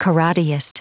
Prononciation du mot karateist en anglais (fichier audio)
Prononciation du mot : karateist